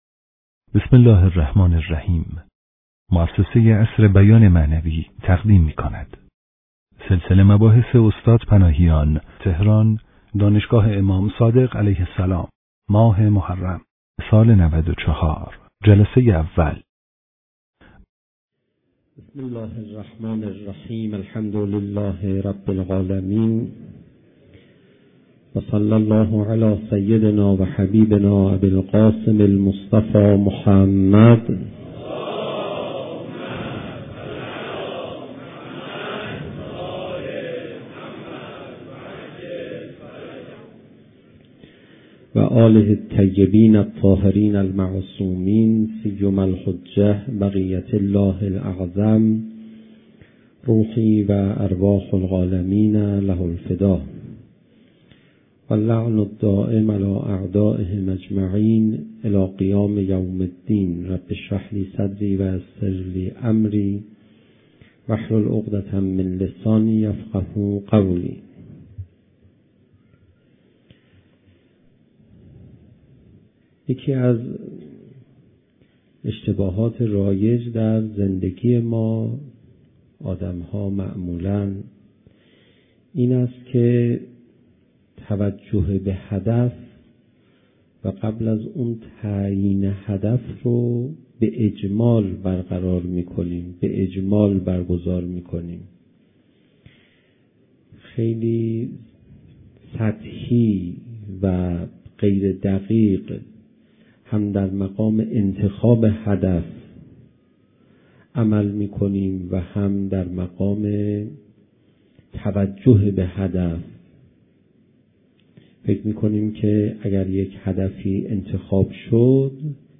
صوت/ سخنرانی پناهیان در شب اول محرم 94
سخنرانی استاد پناهیان در دانشگاه امام صادق(ع) دهه اول ماه محرم ـ سال 94 با موضوع "برای نزدیک شدن به خدا" جلسه اول.